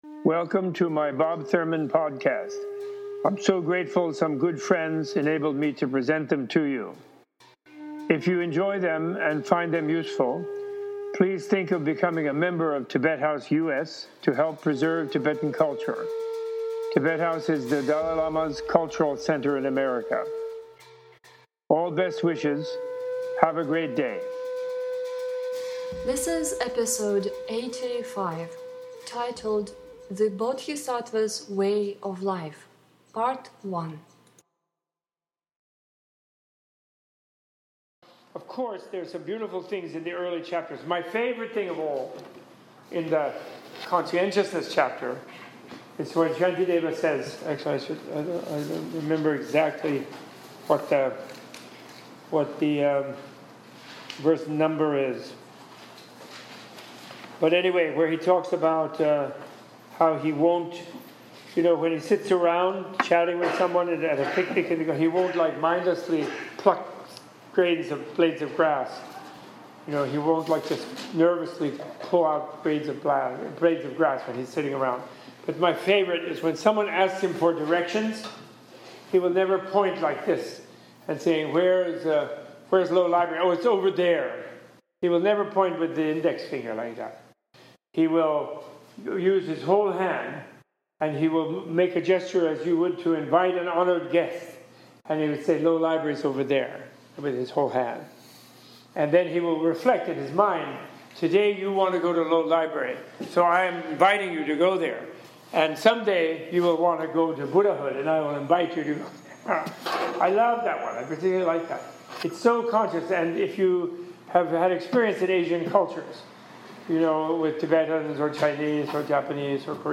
In this episode, Professor Thurman reads from this text the verses dedicated to anger and patience or tolerance, and explains the meaning as he goes along.
This episode was recorded on October 20, 2015 in a class taught by Professor Thurman.